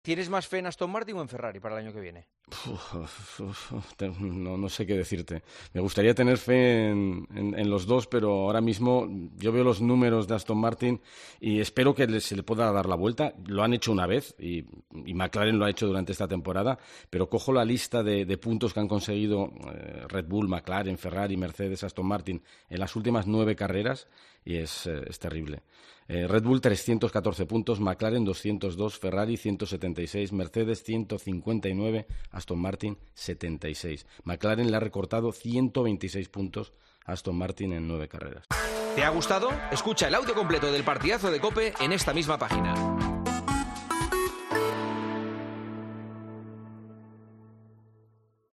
El compañero de El Partidazo de COPE y narrador de las carreras de la Fórmula 1 en DAZN da su opinión de cara a la temporada que viene, ahora que ya Verstappen es campeón del mundo